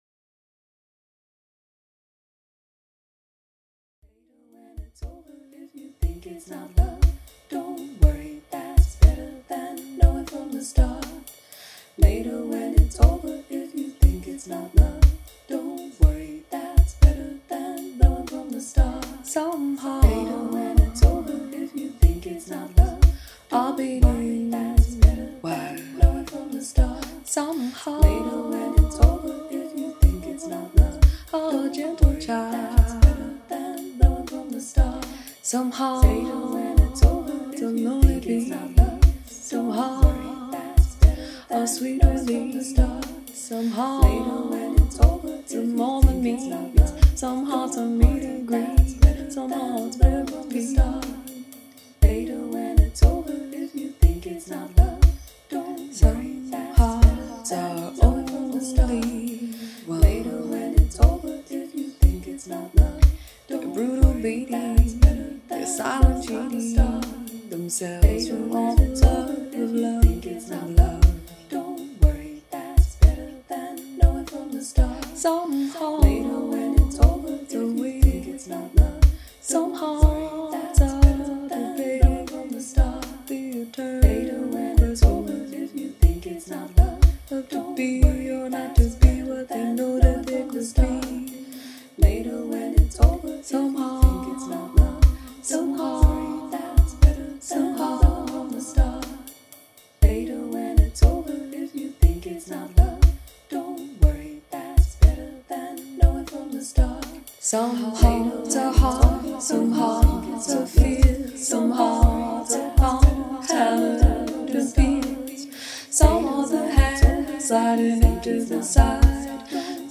Okay, I may have selected the Sade drum kit.